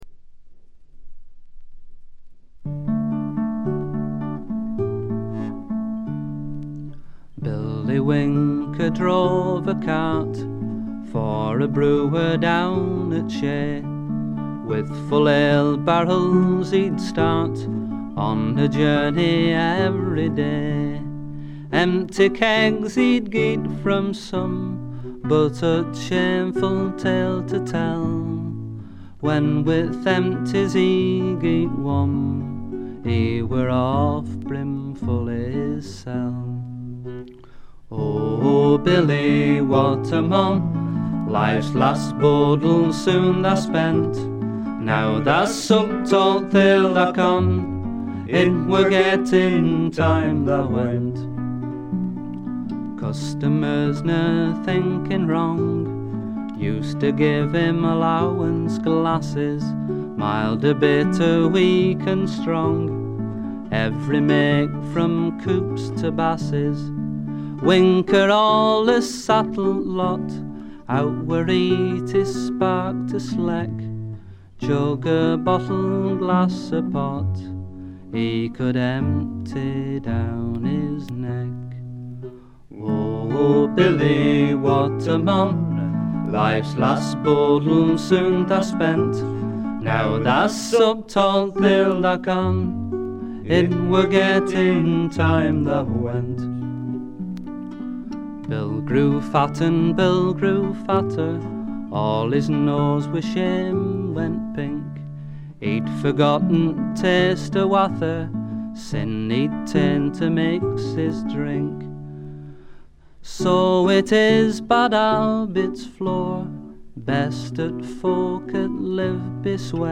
部分試聴ですがほとんどノイズ感無し。
試聴曲は現品からの取り込み音源です。
banjo
guitar
vocals, chorus, mandolin, whistle